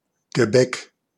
Ääntäminen
Synonyymit puff Ääntäminen US : IPA : [ˈpeɪs.tɹi] Tuntematon aksentti: IPA : /ˈpeɪstri/ Haettu sana löytyi näillä lähdekielillä: englanti Käännös Ääninäyte Substantiivit 1.